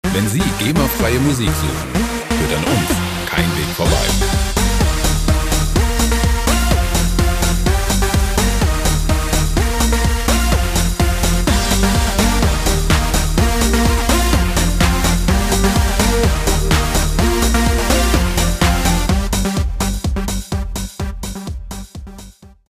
• Classic Techno